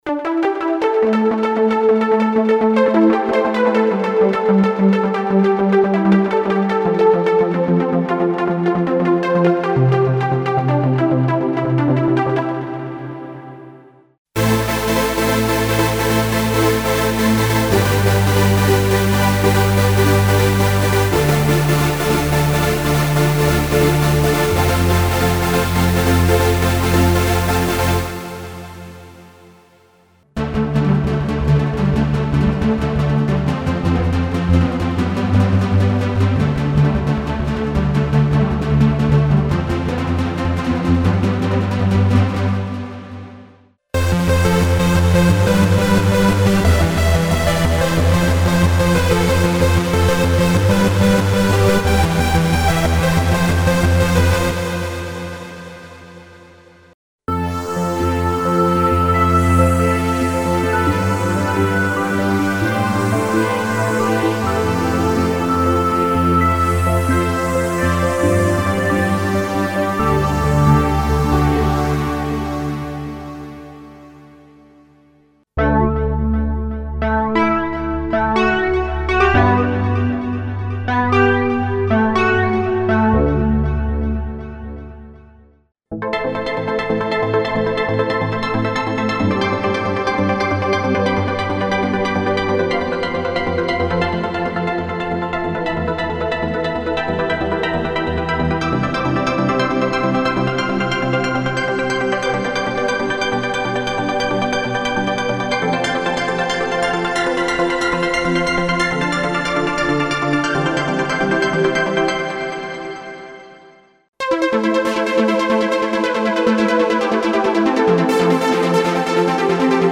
Ich hab mal eine kleine Demo fertig gemacht, das sind einiger der Patches, die ich am basteln bin. Alles per Hand eingespielt, wer nen Fehler findet darf ihn behalten, vielleicht sind ja einige Melodien bekannt Einige Patches haben auch Polyaftertouch, gar nicht so einfach damit vernünftig umzugehen am Anfang, klingt dann teils noch etwas Hakelig.